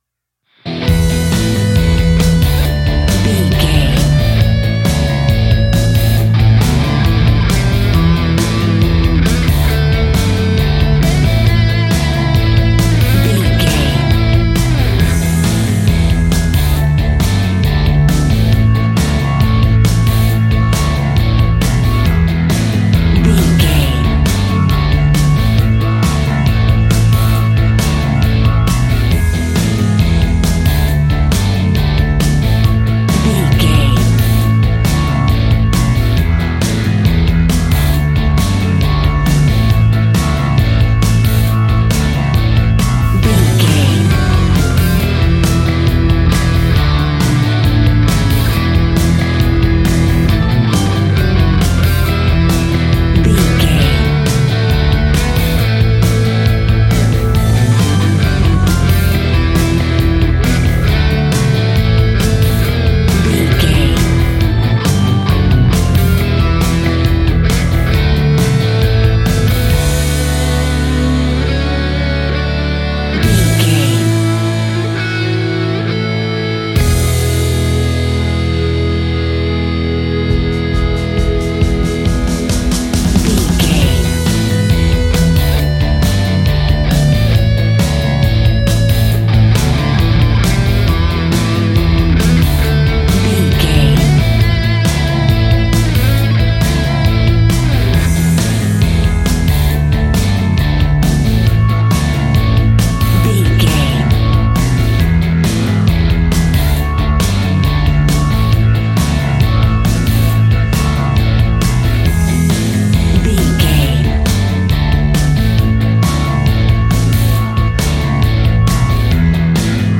Fast paced
Ionian/Major
hard rock
distortion
punk metal
instrumentals
Rock Bass
heavy drums
distorted guitars
hammond organ